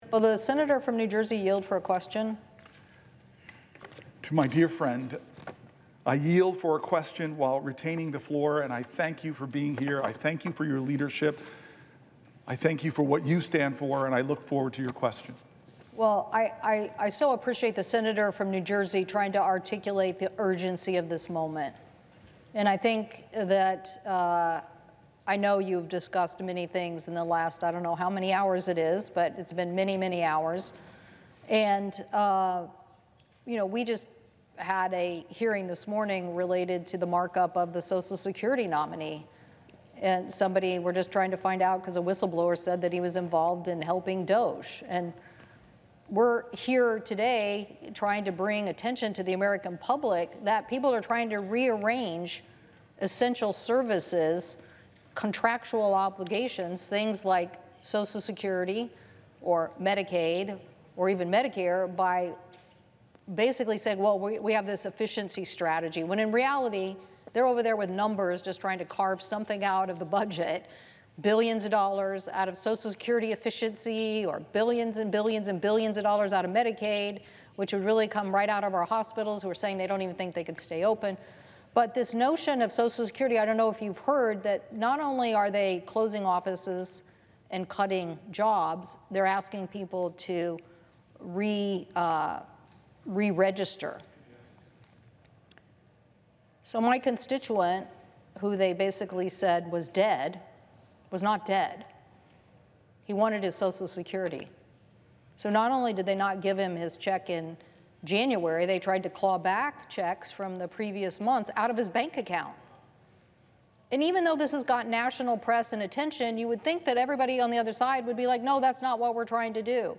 Cantwell Joins Booker in Marathon Floor Speech Against Trump Agenda | U.S. Senator Maria Cantwell of Washington
WASHINGTON, D.C. – Today, U.S. Senator Maria Cantwell (D-WA), senior member of the Senate Finance Committee and ranking member of the Senate Committee on Commerce, Science, and Transportation, joined Senator Cory Booker (D-NJ) on the Senate floor to speak out against President Trump’s budget resolution, which proposes up to an $880 billion cut from Medicaid, and DOGE’s proposals to shut down Social Security offices and lay off 7,000 workers, making it harder for Americans to access the benefits they earned and are entitled to.